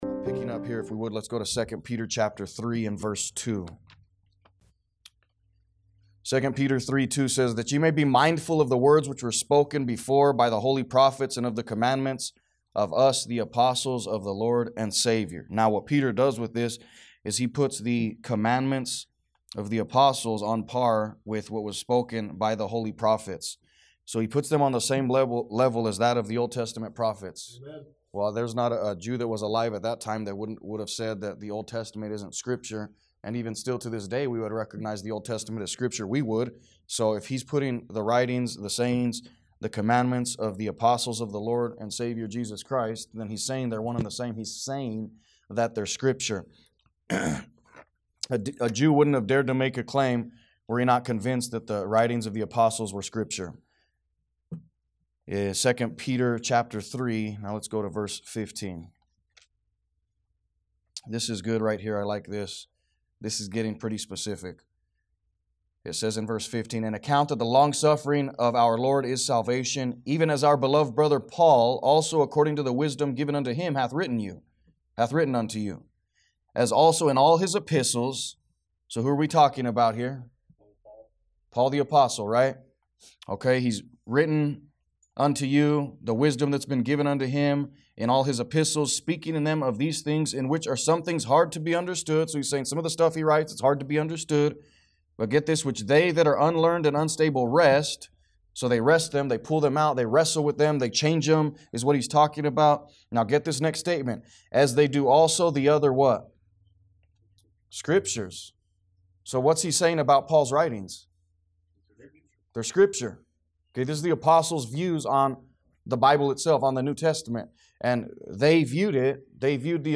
A message from the series "Doctrine of The Bible."